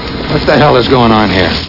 Amiga 8-bit Sampled Voice
1 channel
hurry.mp3